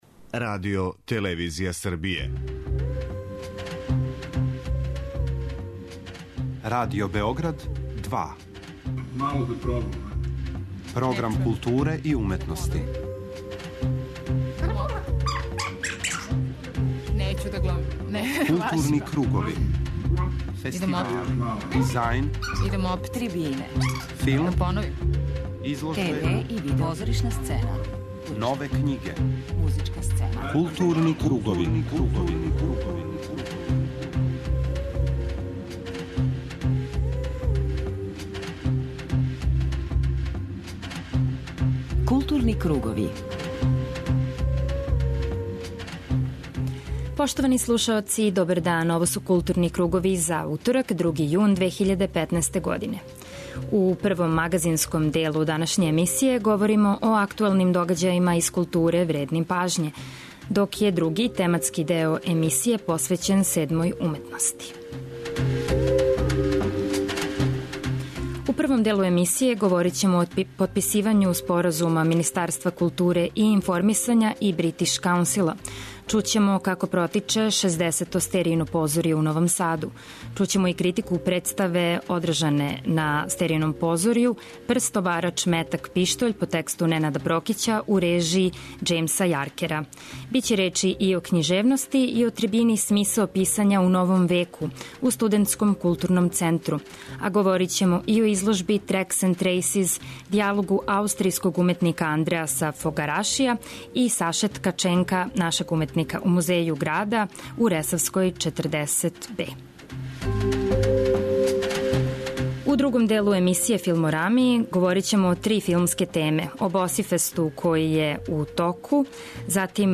На почетку данашњег темата чућете како је протекла конференција за новинаре на којој је најављен 22. Фестивал европског филма Палић и представљен програм фестивала, који ће ове године бити одржан од 18. до 24. јула на више локација на Палићу и у Суботици.